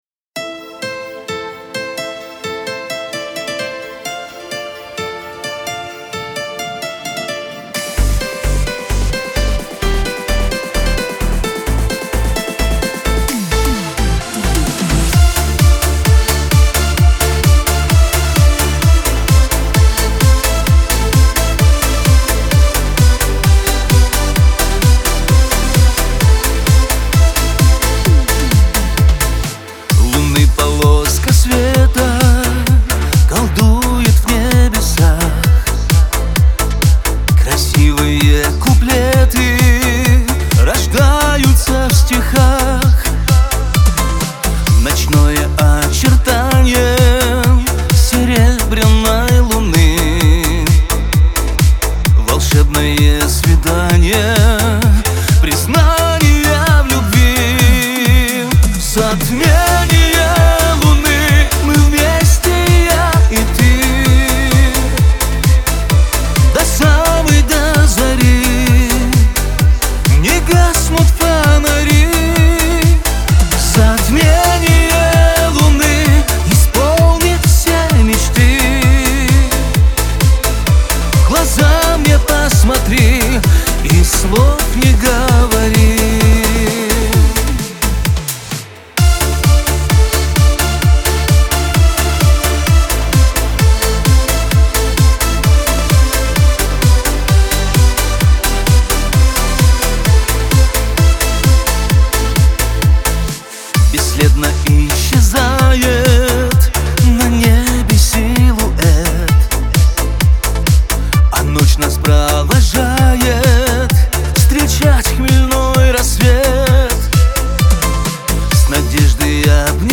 диско
pop
эстрада